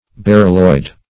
Search Result for " berylloid" : The Collaborative International Dictionary of English v.0.48: Berylloid \Ber"yl*loid\, n. [Beryl + -oid.]